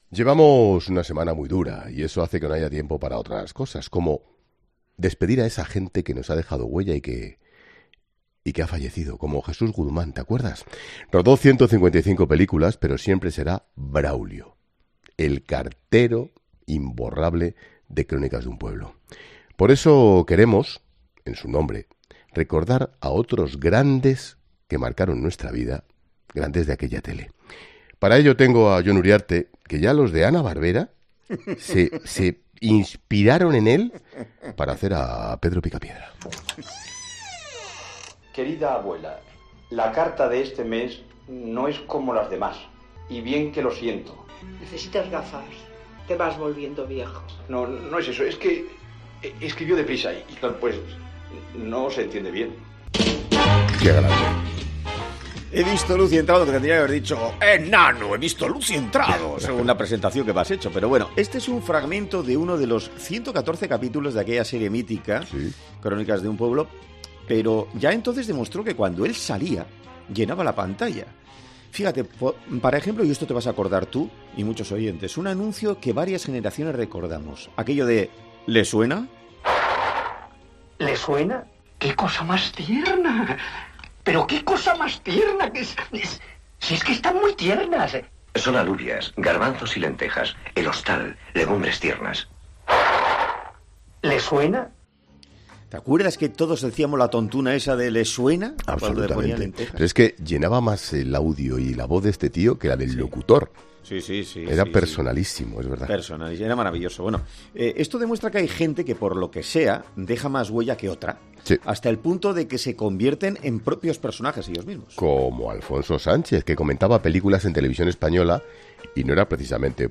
Expósito se salta el guion para mandar un mensaje en directo a José Luis Gil por su estado de salud: "Ojalá"
“¿A que reconoces su voz de eterno abuelito, pequeño, calvo y con gafas?”, describen mientras le escuchan en directo.